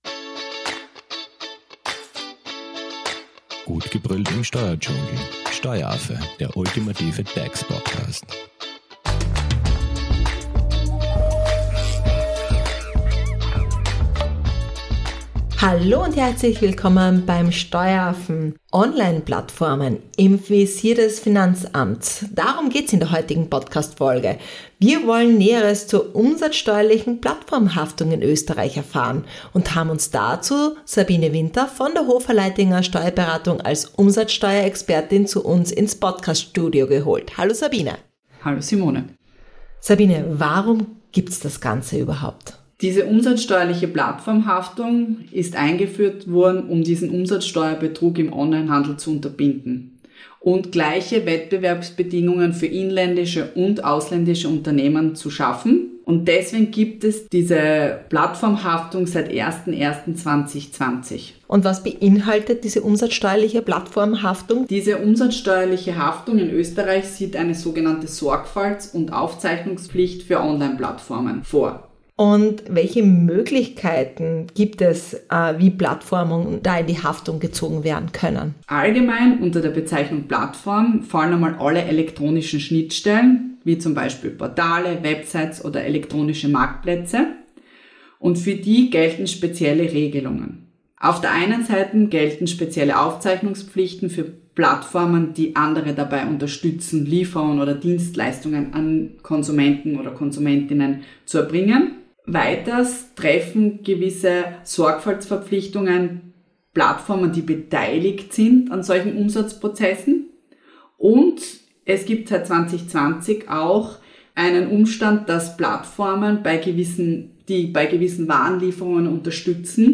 Außerdem erfährt ihr, welche Haftungskriterien bestehen und wie die umsatzsteuerliche Plattformhaftung in Österreich umgesetzt wird. Zu Gast im Steueraffen-Studio ist Umsatzsteuerexpertin